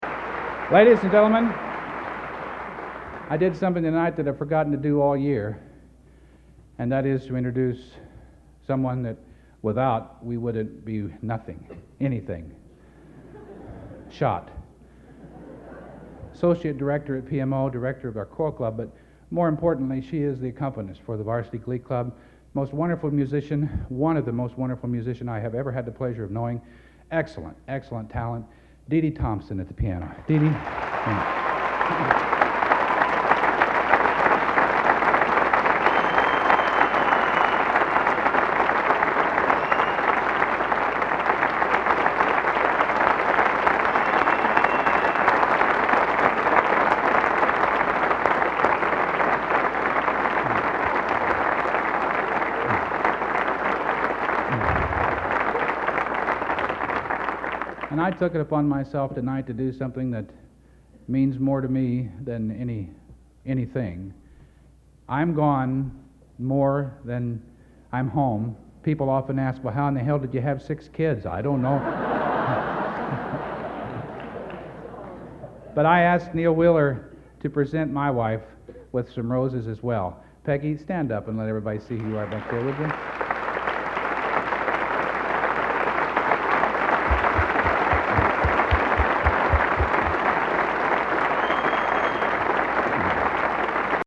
Collection: End of Season, 1986
Location: West Lafayette, Indiana
Genre: | Type: Director intros, emceeing |End of Season